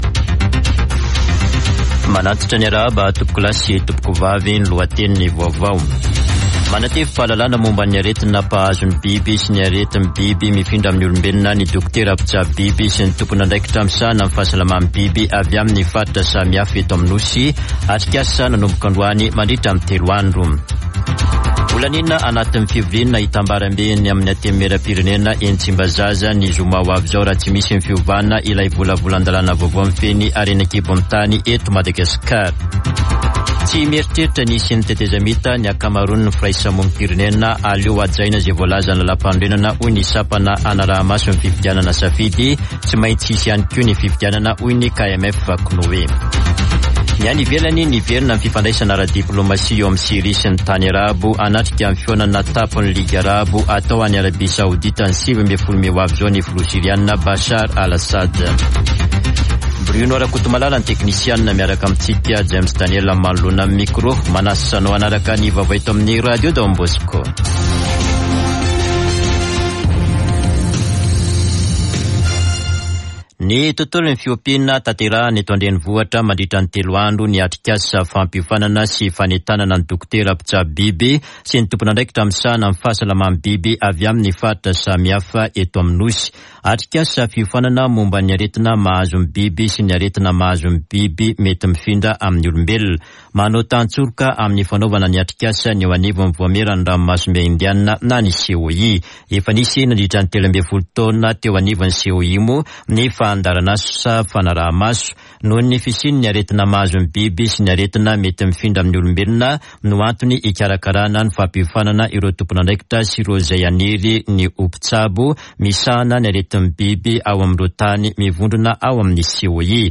[Vaovao antoandro] Alakamisy 11 mey 2023